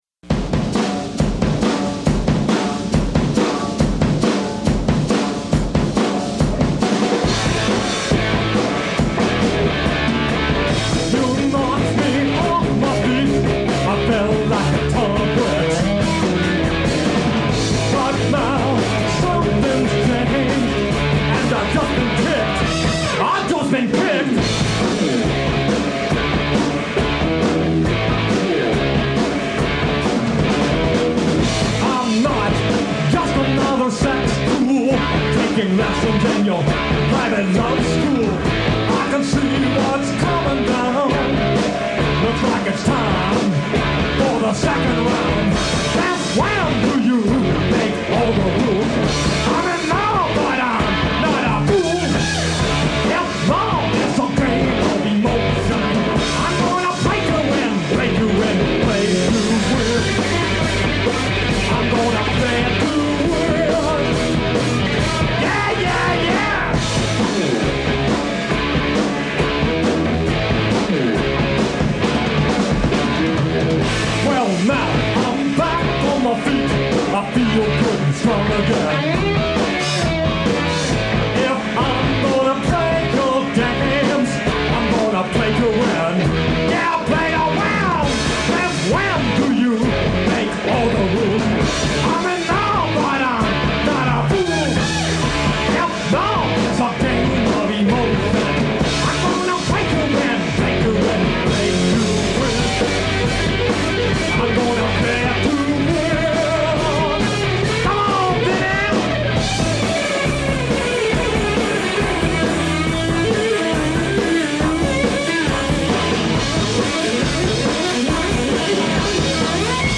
punk rock band